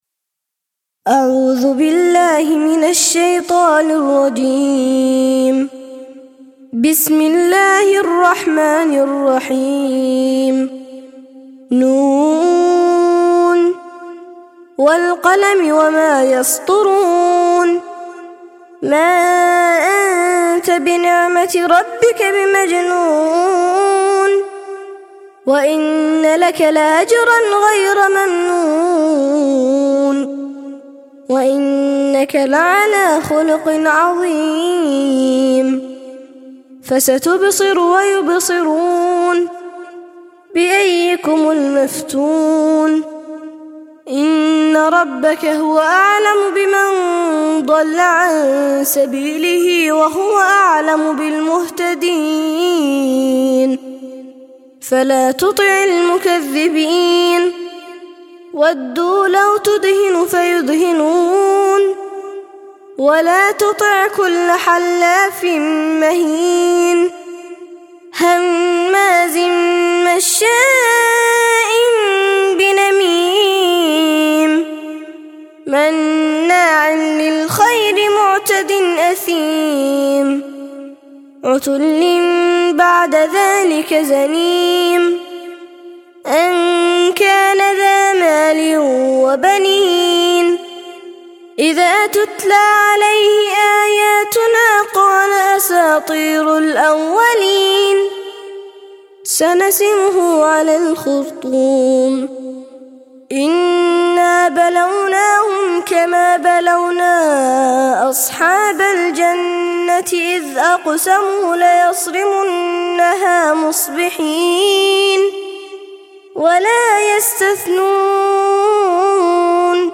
68- سورة القلم - ترتيل سورة القلم للأطفال لحفظ الملف في مجلد خاص اضغط بالزر الأيمن هنا ثم اختر (حفظ الهدف باسم - Save Target As) واختر المكان المناسب